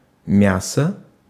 Ääntäminen
Ääntäminen RP : IPA : /ˈtɪs.juː/ GenAm: IPA : /ˈtɪʃ.u/ US : IPA : [ˈtɪʃ.u] UK : IPA : [ˈtɪs.juː]